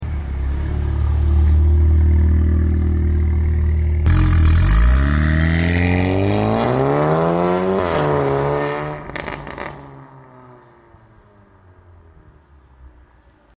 • Rogue Performance Valvetronic Turbo-Back Exhaust.